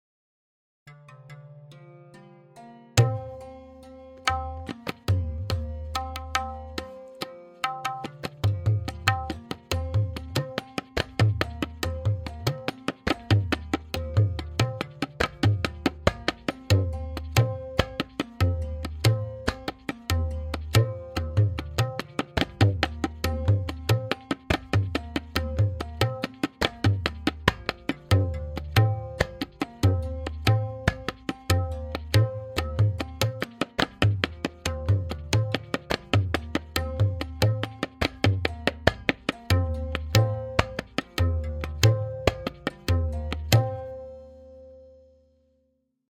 Note: The following forms are all played in dugun (2:1).
Sadharan Chakradar with Theka and Lahra
M8.5-Sadharan-Theka-Lehra.mp3